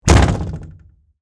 Download Door Slam sound effect for free.
Door Slam